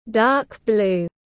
Dark blue /dɑ:k bluː/